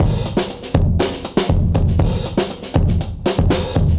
Index of /musicradar/dusty-funk-samples/Beats/120bpm/Alt Sound
DF_BeatA[dustier]_120-02.wav